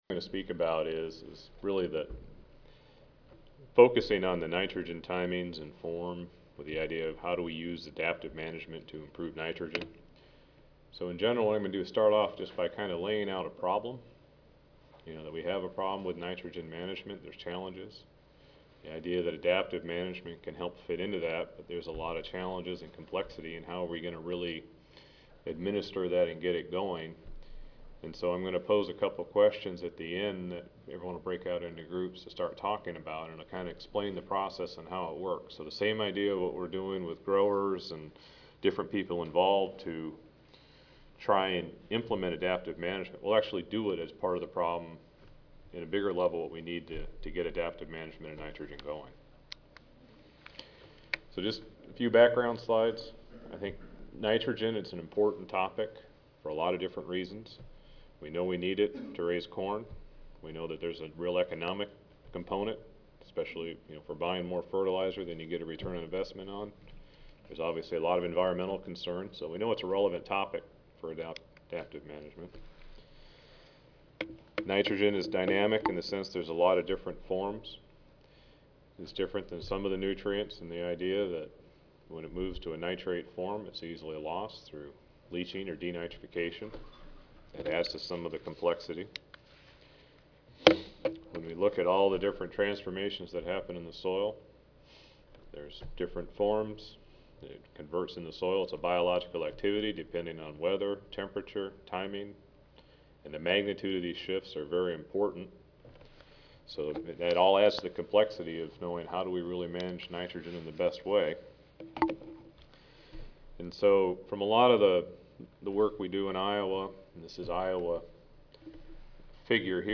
Iowa Soybean Association Audio File Recorded presentation